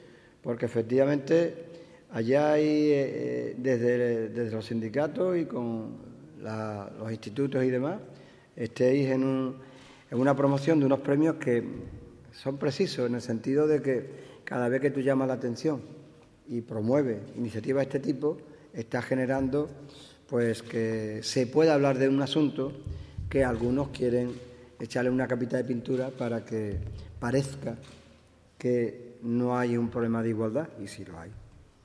En su discurso, el vicepresidente ha agradecido tanto al sindicato organizador como a los centros participantes en el concurso el impulso a estos premios “que son precisos, en el sentido de que cada vez que se promueven iniciativas de este tipo se genera que se pueda hablar de unos asuntos a los que algunos quieren echarles una capita de pintura para que parezca que no hay un problema de igualdad. Y sí lo hay”.